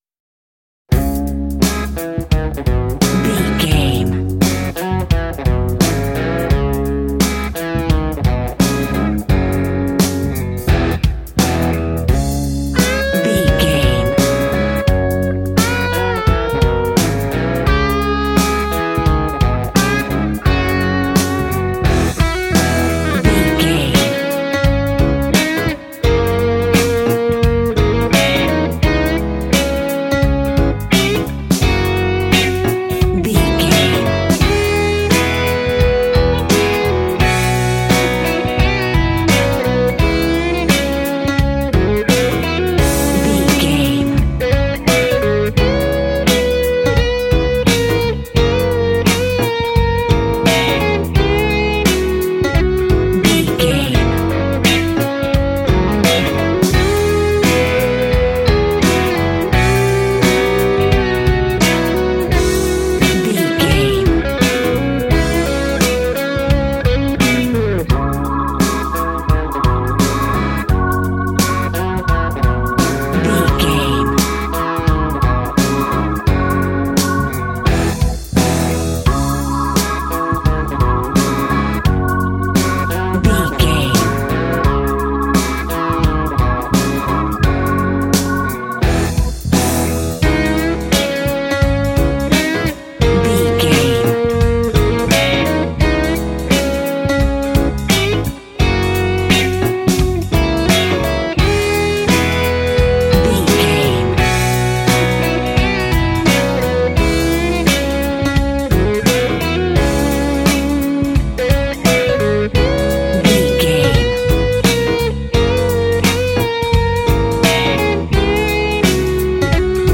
Aeolian/Minor
D♭
sad
mournful
bass guitar
electric guitar
electric organ
drums